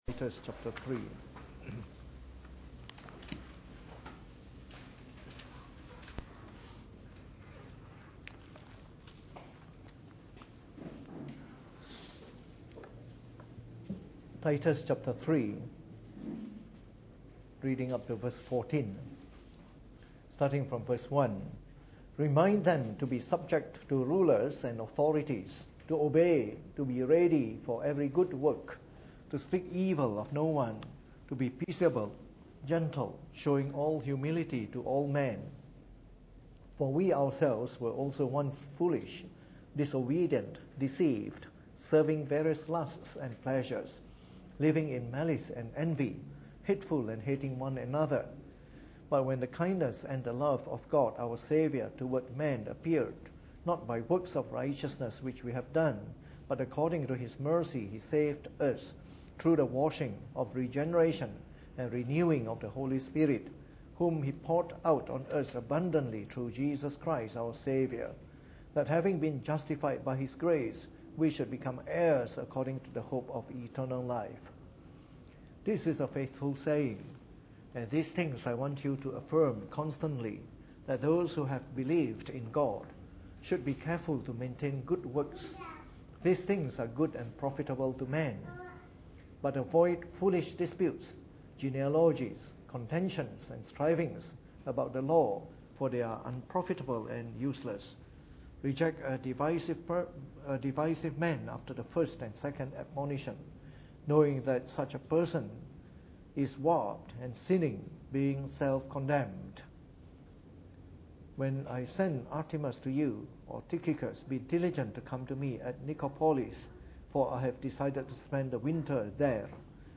Preached on the 12th of April 2017 during the Bible Study, from our series on Semper Reformanda.